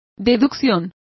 Complete with pronunciation of the translation of deduction.